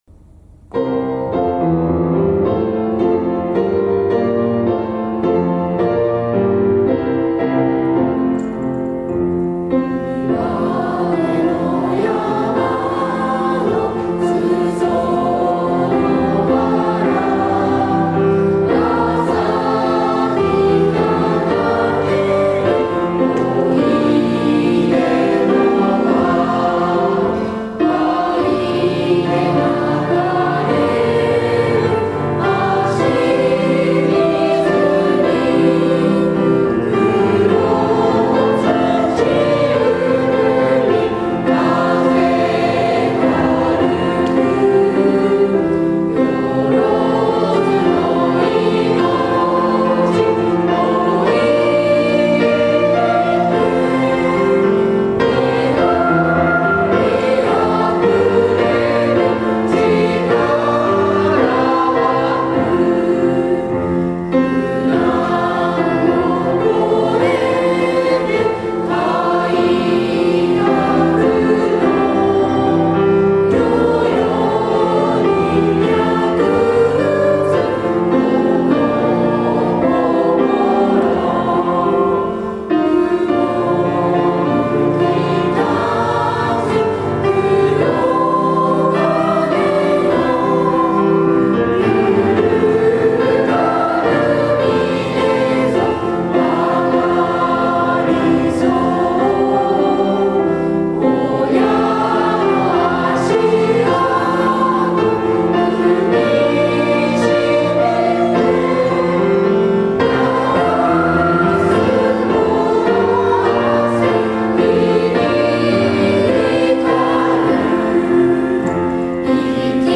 伸び伸びと讃歌を歌いました
R6_1217_讃歌_児童先生_E.mp3